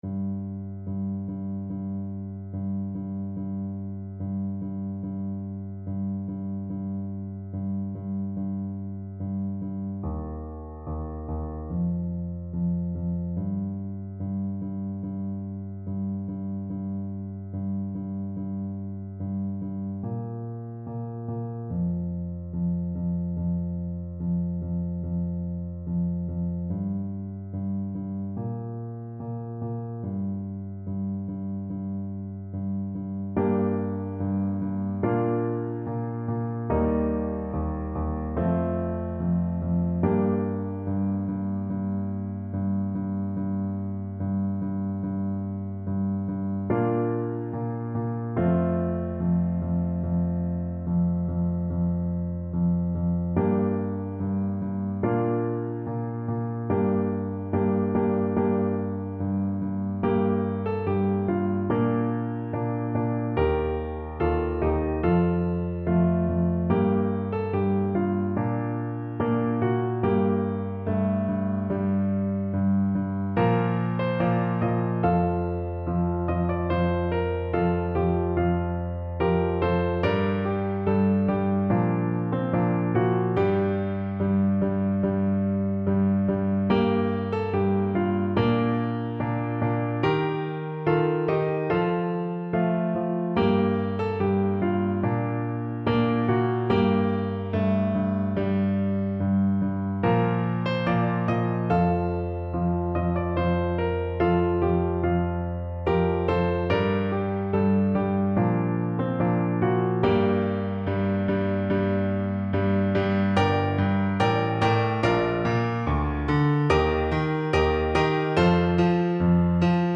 Play (or use space bar on your keyboard) Pause Music Playalong - Piano Accompaniment Playalong Band Accompaniment not yet available transpose reset tempo print settings full screen
Trumpet
Traditional Music of unknown author.
4/4 (View more 4/4 Music)
G minor (Sounding Pitch) A minor (Trumpet in Bb) (View more G minor Music for Trumpet )
Moderato =c.100